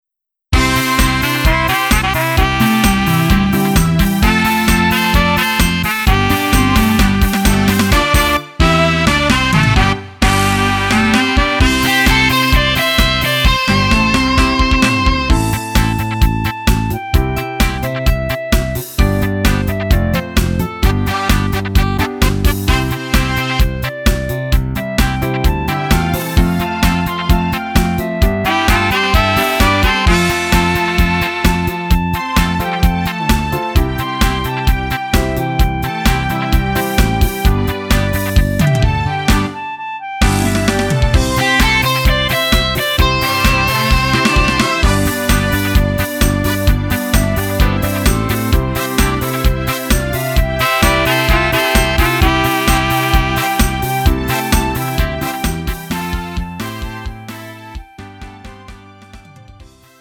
음정 원키 3:15
장르 구분 Lite MR